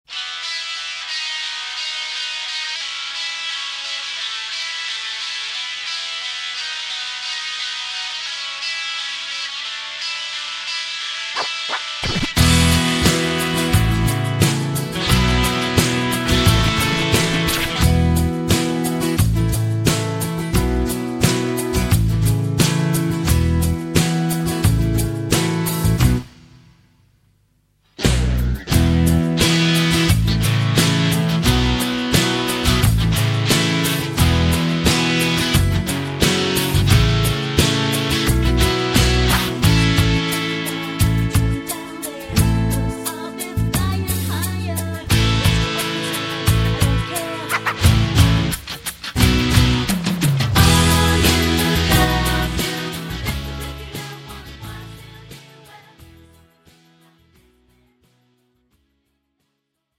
(팝송) MR 반주입니다.